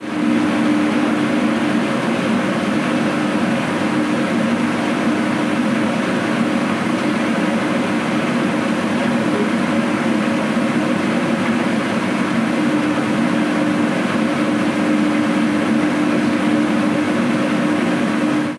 Ruido de una lavadora aclarando
lavadora
Sonidos: Agua
Sonidos: Hogar